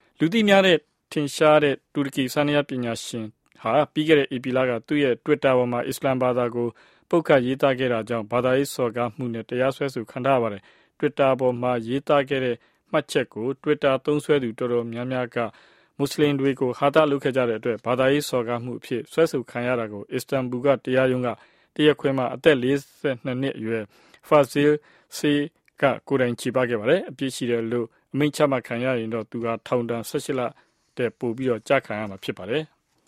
Turkish pianist Fazil Say